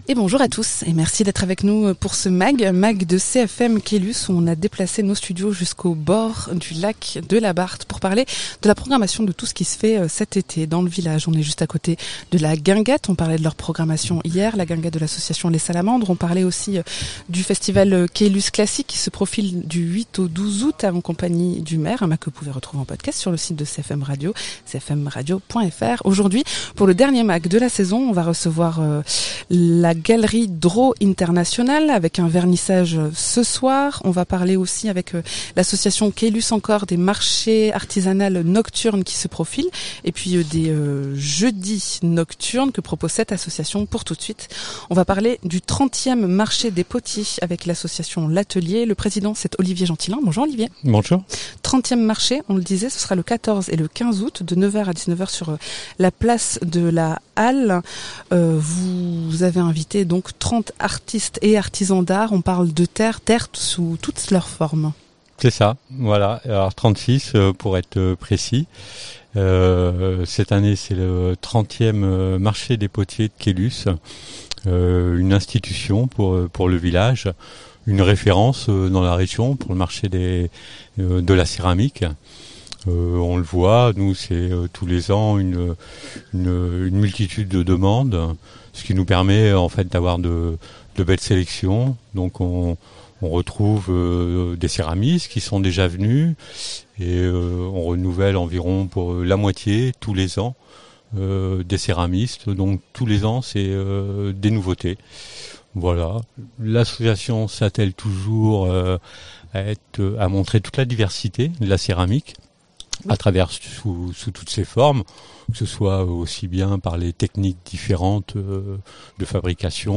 Un mag en extérieur au bord du lac de Caylus à propos du 30ème marché des potiers de Caylus ce 14 et 15 Août où on attend 36 artistes et artisans.
Interviews